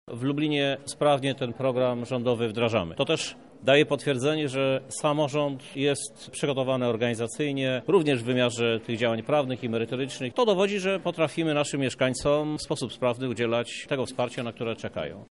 – mówi Krzysztof Żuk, prezydent miasta.